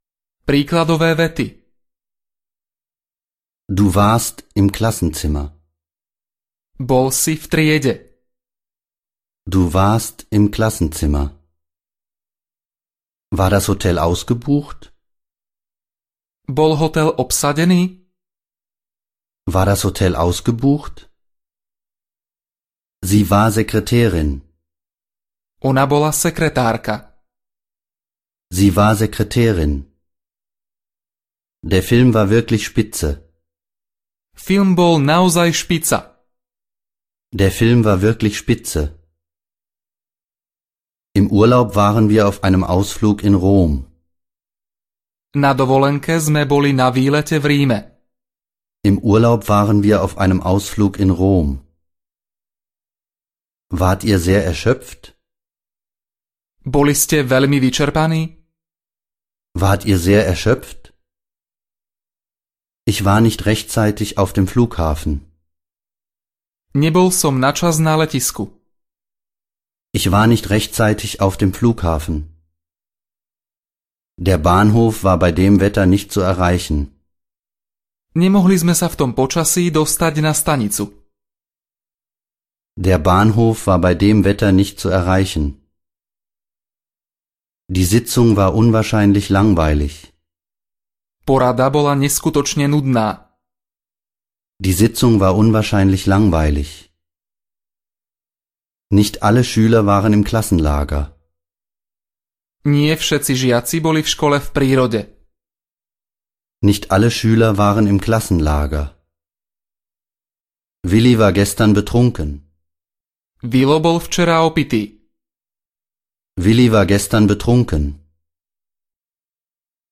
Nemčina pre všetkých 6 audiokniha
Ukázka z knihy